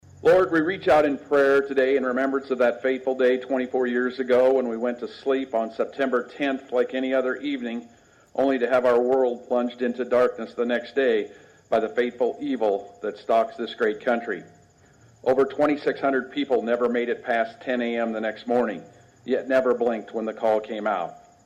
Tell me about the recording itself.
(Atlantic) The American Legion Post #43 held a Remembrance Day ceremony Thursday morning at the Atlantic City Park to honor first responders for their heroic efforts during the current day and the September 11, 2001, attacks on the United States.